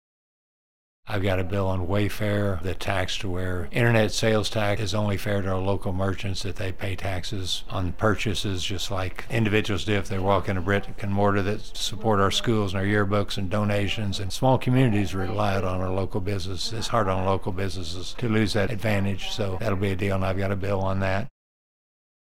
3. Senator Cunningham also says he wants to protect small businesses.